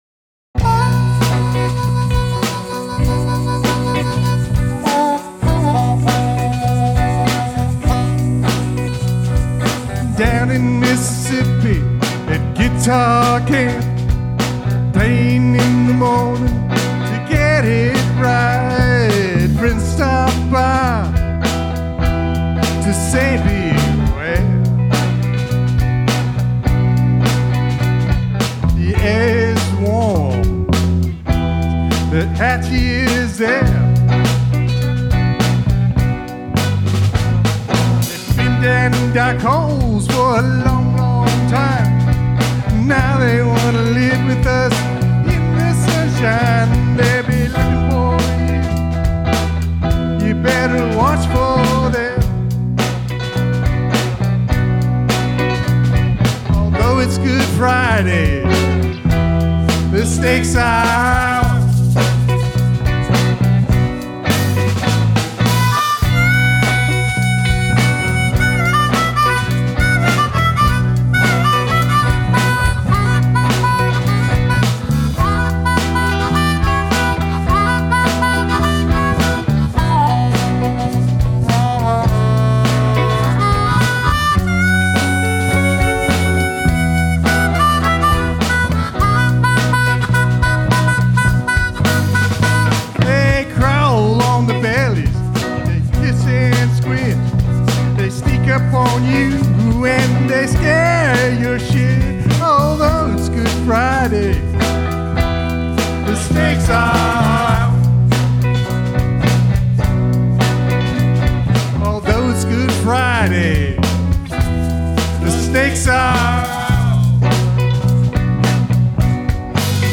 Recorded in Clarksdale MS